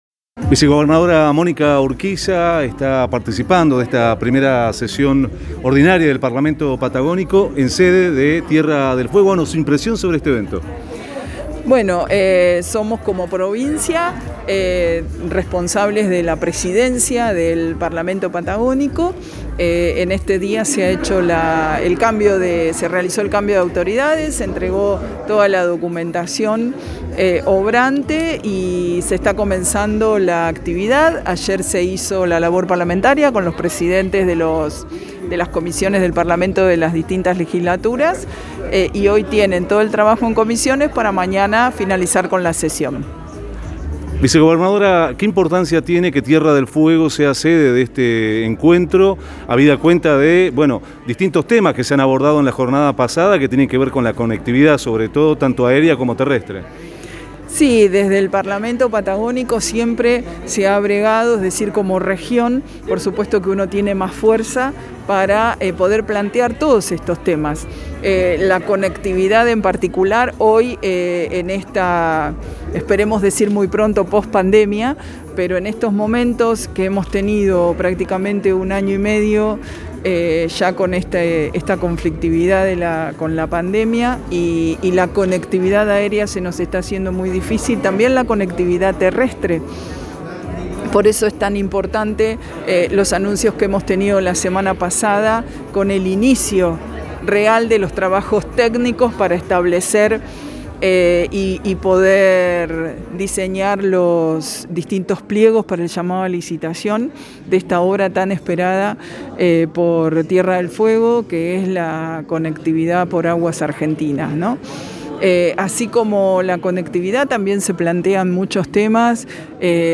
1ª Sesión del Parlamento Patagónico declaraciones de los legisladores